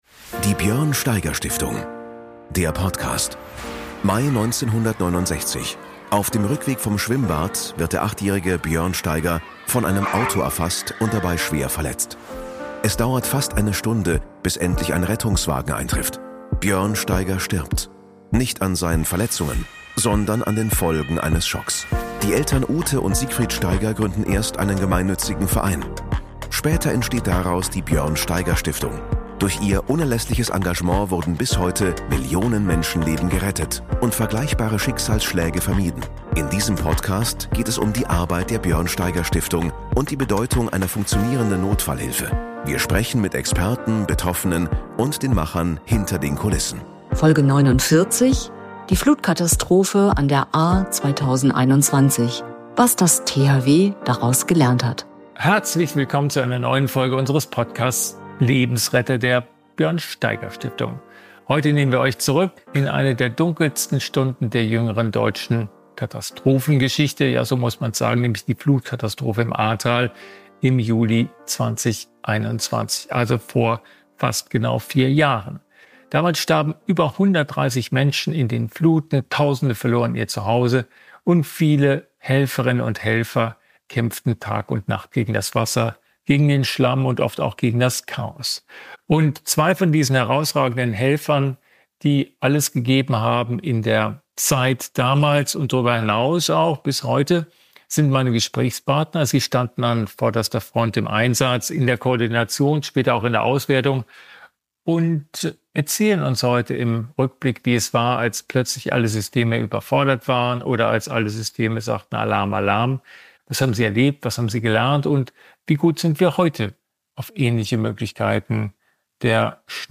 Ein Gespräch über Mut, Überforderung, Menschlichkeit – und darüber, warum das Ehrenamt im Katastrophenschutz unersetzlich ist.